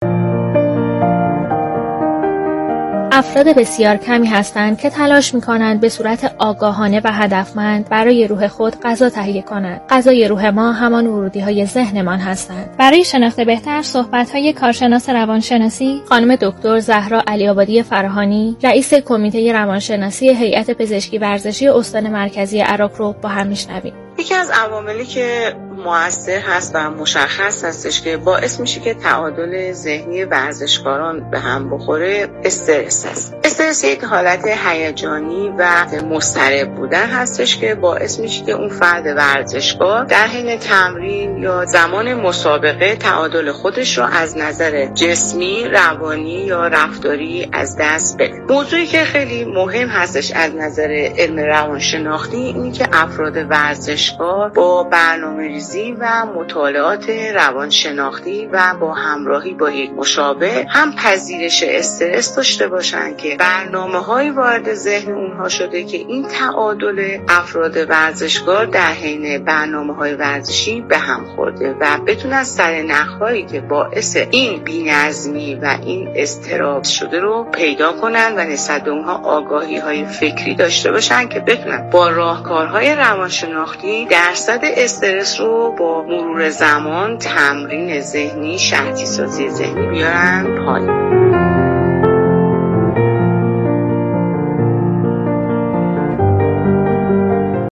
/پزشکی ورزشی و رادیو ورزش/